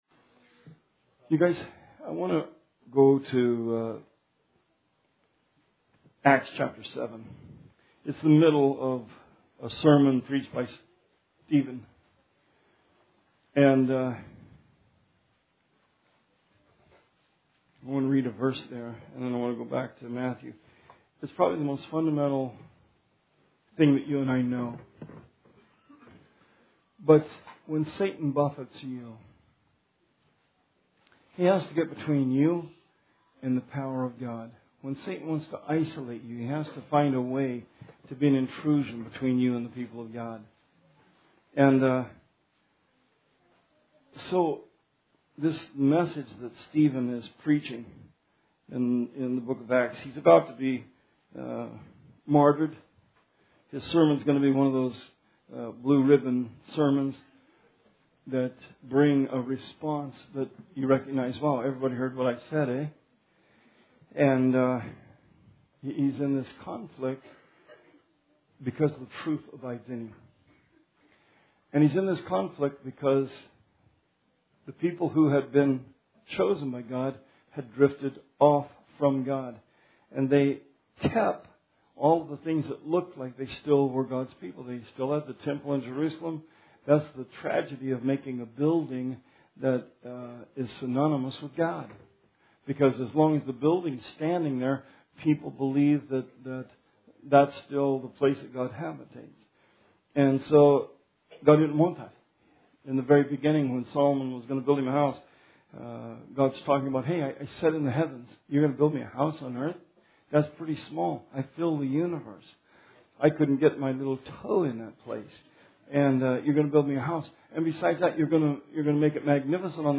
Series Sermon